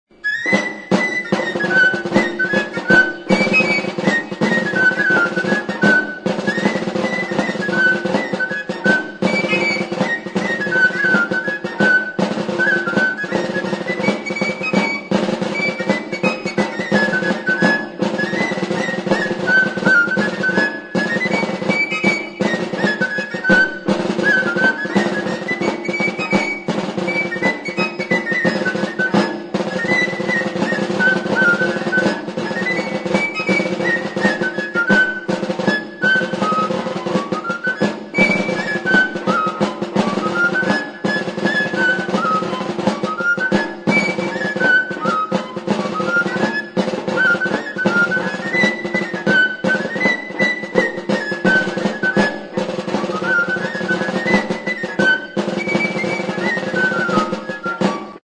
Aerófonos -> Flautas -> Recta (de una mano) + flautillas
HIRU PUNTUKOA (mutil-dantza).
Arizkun, 1984.
Hiru zuloko flauta zuzena da.
Fa #-n afinaturik dago.